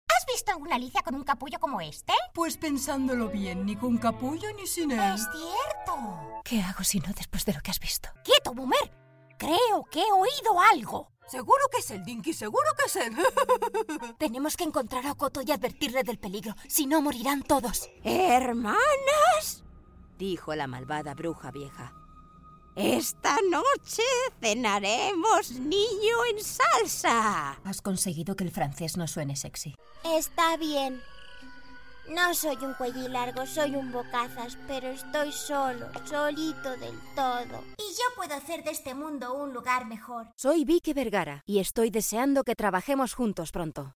Commercial, Young, Natural, Versatile, Soft